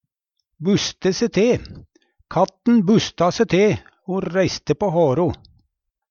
buste se te - Numedalsmål (en-US)